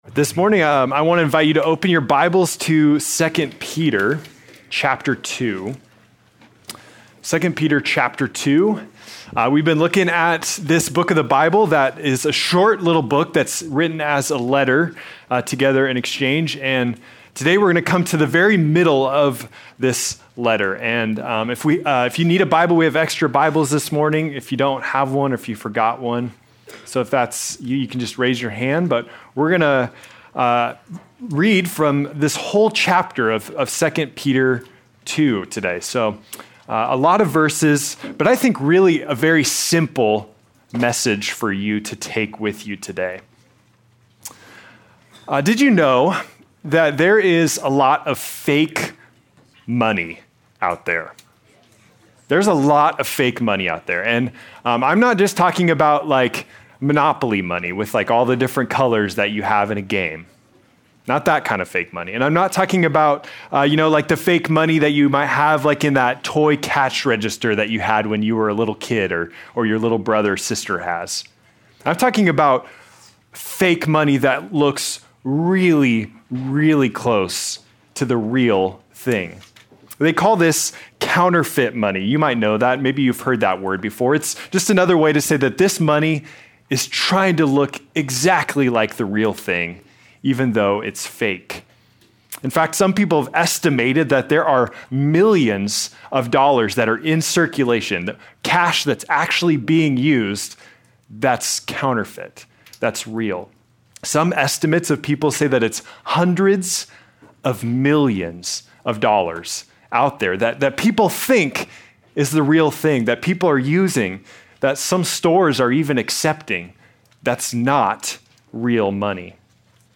April 12, 2026 - Sermon